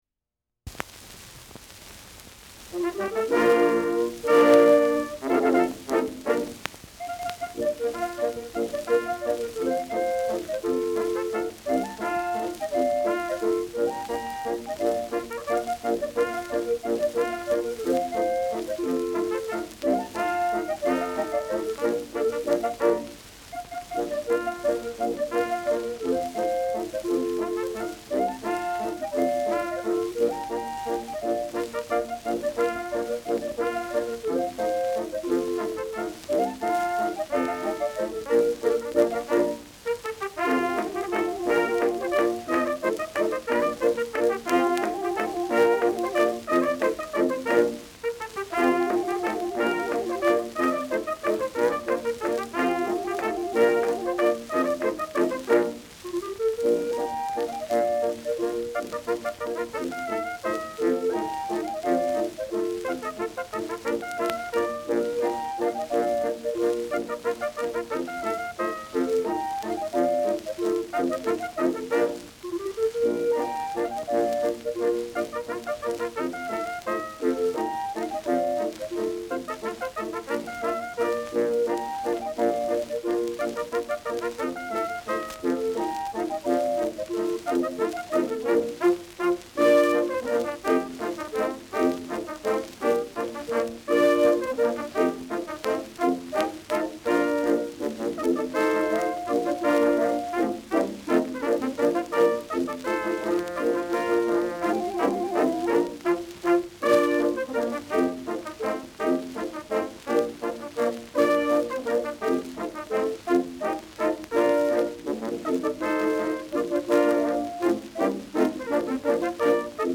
Schellackplatte
Tonrille: Deformiert : Kratzer 2 Uhr Stark
Leichtes Grundrauschen : Vereinzelt leichtes Knacken
[Berlin] (Aufnahmeort)